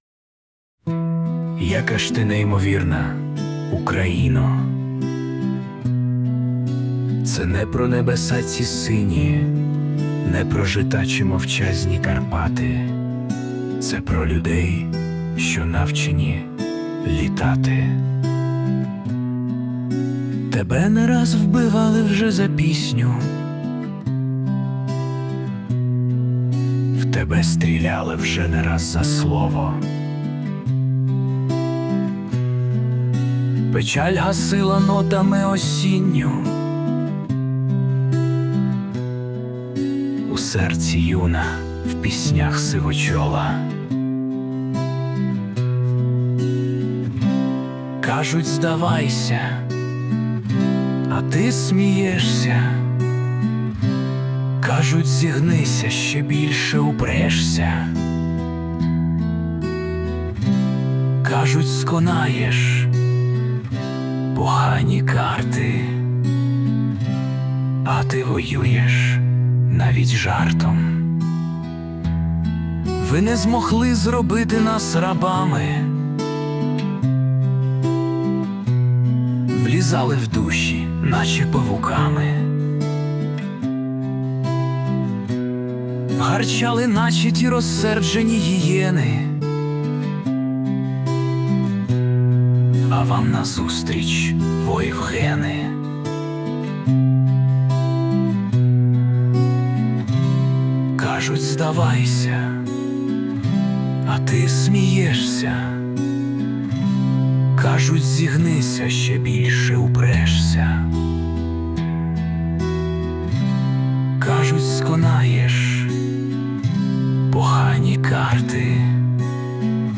Власне виконання
Перепади звуку усунуто
СТИЛЬОВІ ЖАНРИ: Ліричний
Гарне, чуттеве виконання! 16 22 22 hi